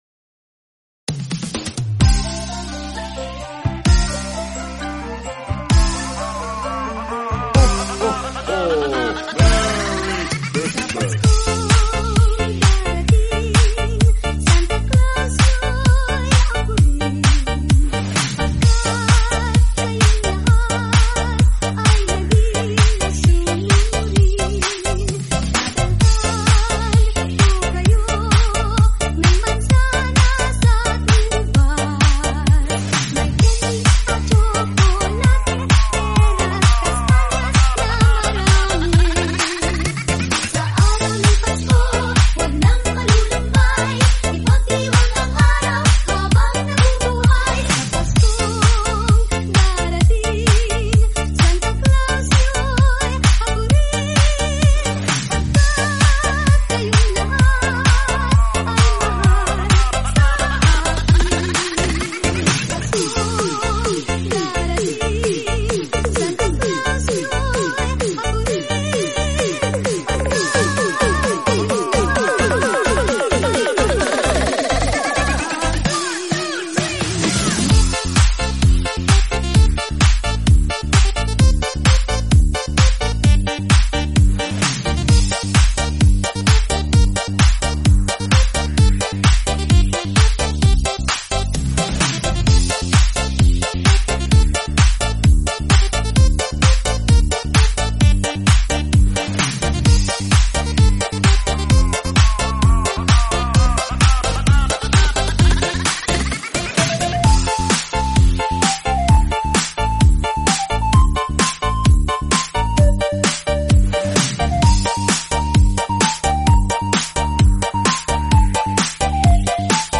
BREAK LATIN REMIX